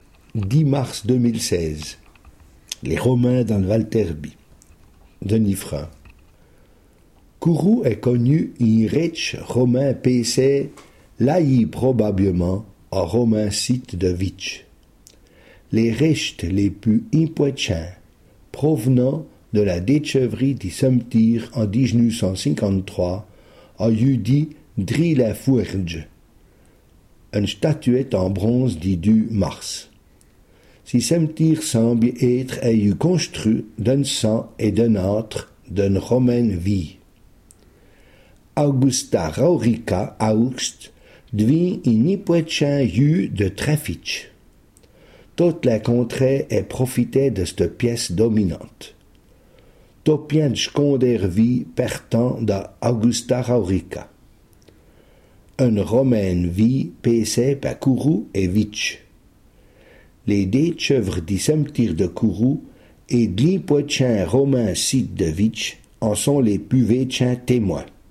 Sommaire Ecouter le r�sum� en patois Panneau complet, consulter ou Situation ; coordonn�es (...)